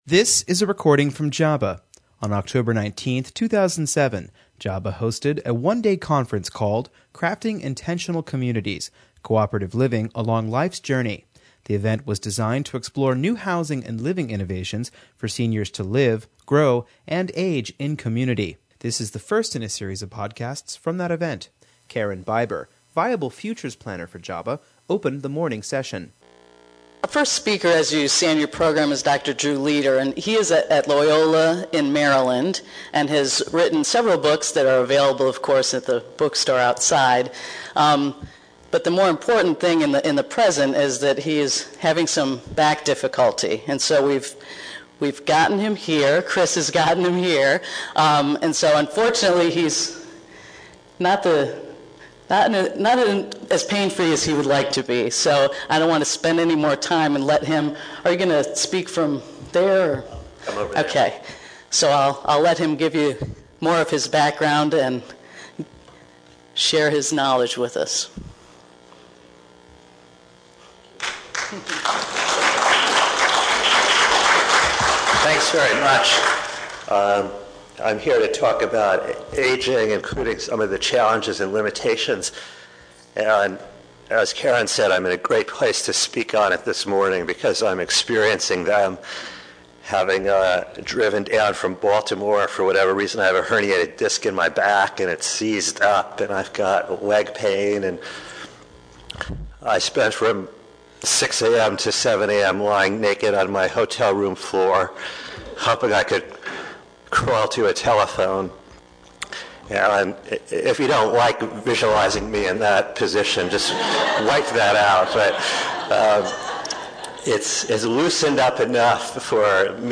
On October 19, 2007, the Jefferson Area Board for Aging hosted a one-day conference called Crafting Intentional Communities: Cooperative Living Along Life’s Journey. The event was designed to explore new housing and living innovations for seniors to live, grow and age in community.